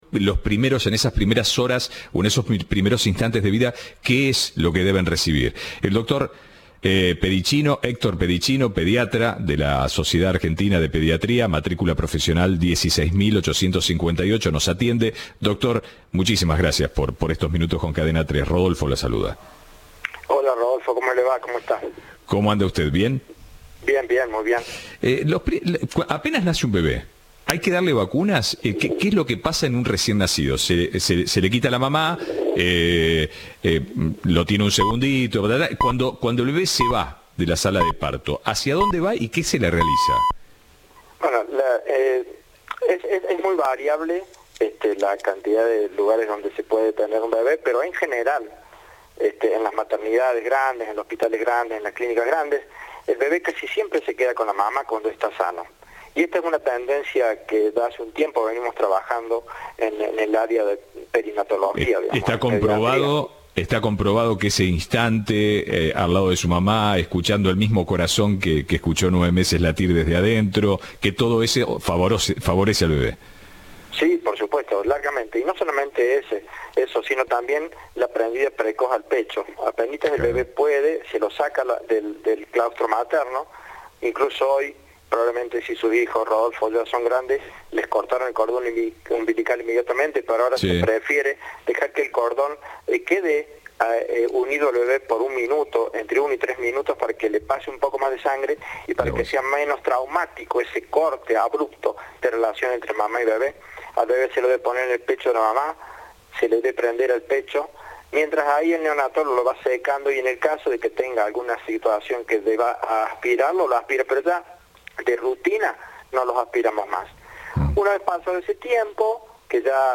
Entrevista de "Ahora país".